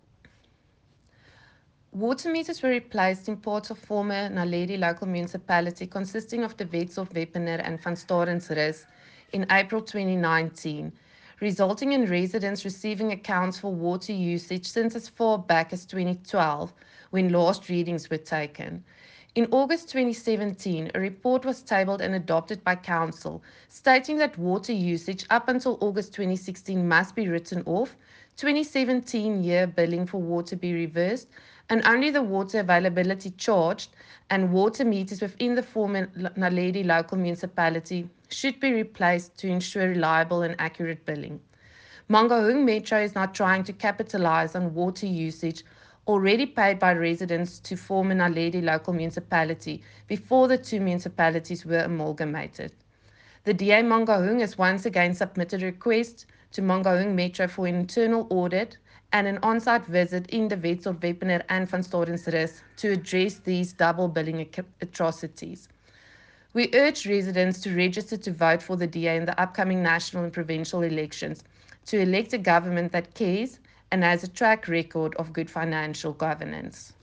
Afrikaans soundbites by Cllr Maryke Davies and Sesotho by Cllr David Masoeu.